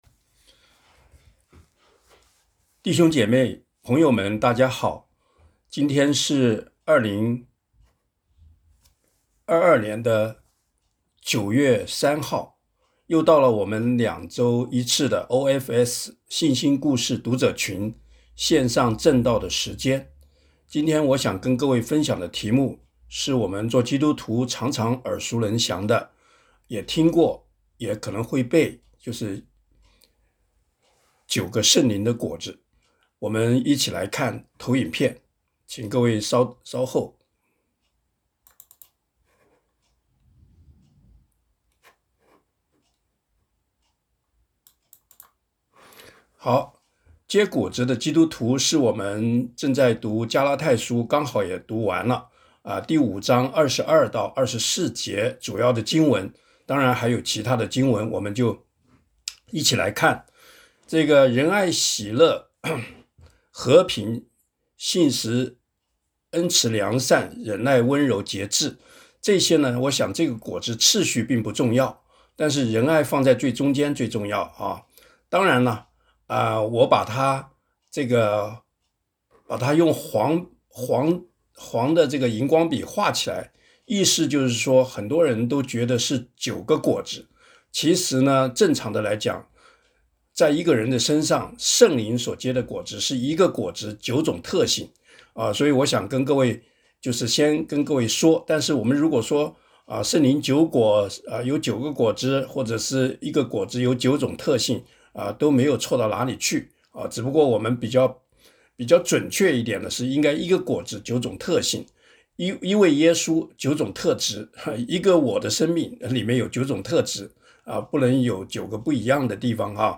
今天是每两周1次的主日时间。